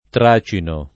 Tracino [ tr #© ino ]